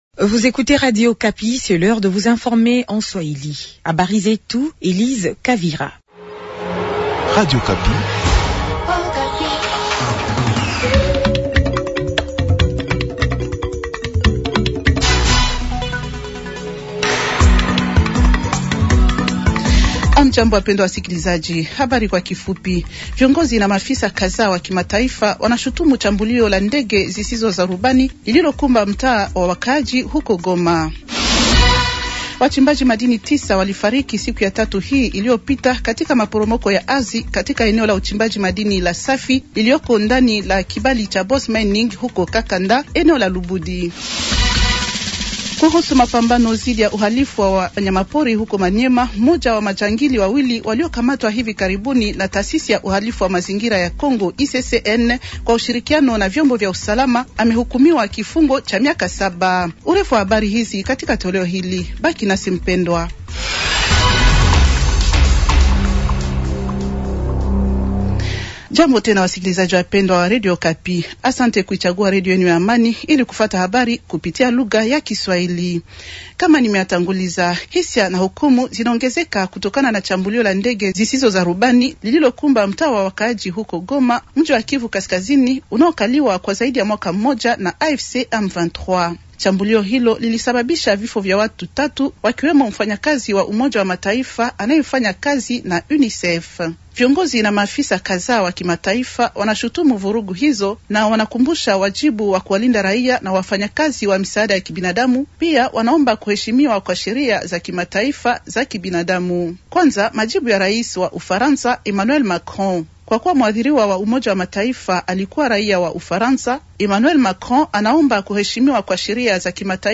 Journal swahili de jeudi soir 120326